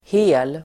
Uttal: [he:l]